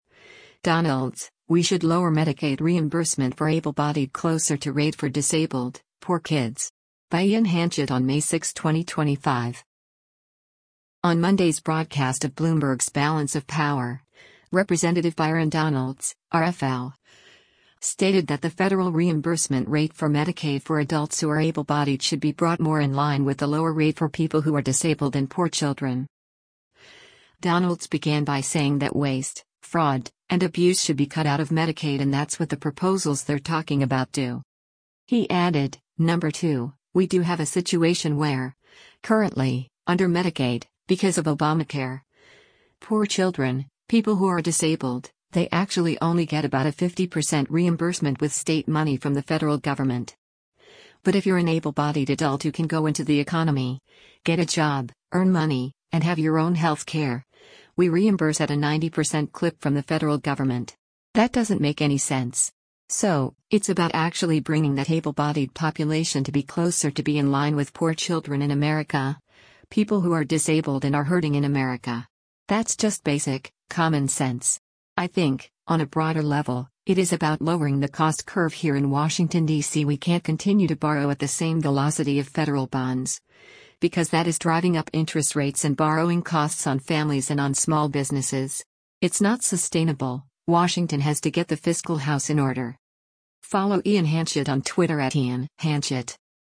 On Monday’s broadcast of Bloomberg’s “Balance of Power,” Rep. Byron Donalds (R-FL) stated that the federal reimbursement rate for Medicaid for adults who are able-bodied should be brought more in line with the lower rate for people who are disabled and poor children.